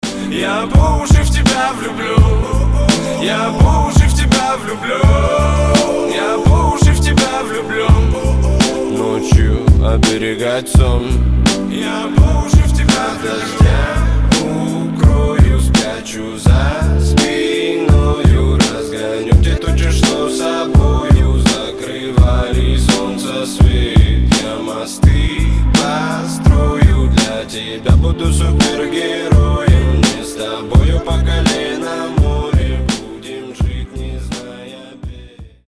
Рэп рингтоны
Хип-хоп